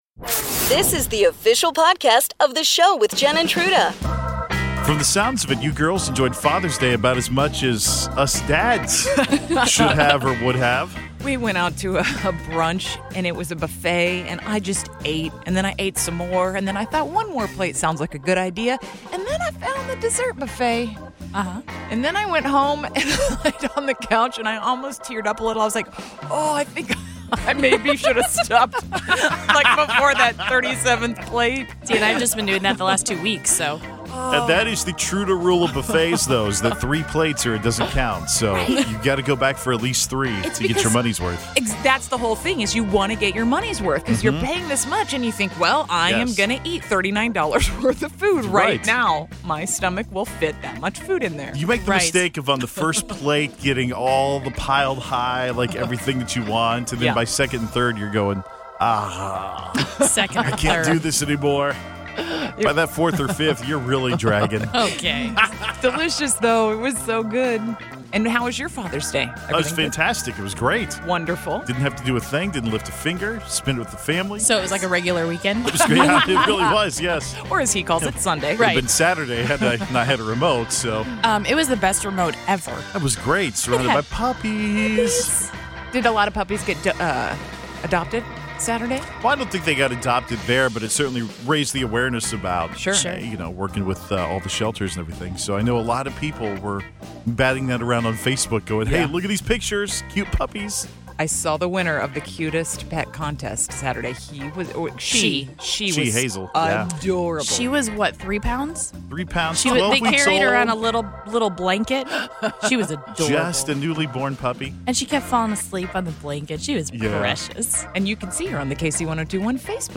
We ask how you passed the time while your power was out? Comedian/Actor Will Forte (SNL, Last Man On Earth) called in to chat about this week's Big Slick event, his softball prowess and how his sexiness affects the game.